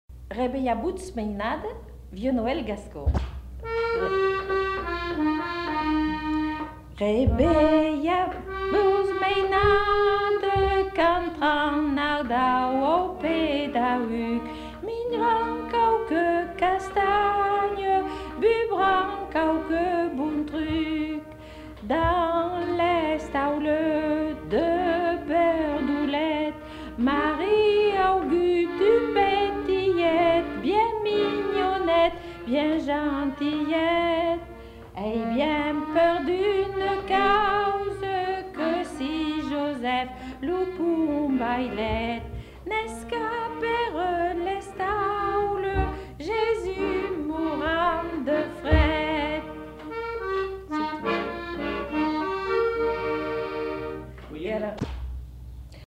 Aire culturelle : Bazadais
Lieu : La Réole
Type de voix : voix de femme
Production du son : chanté
Instrument de musique : accordéon chromatique
Classification : noël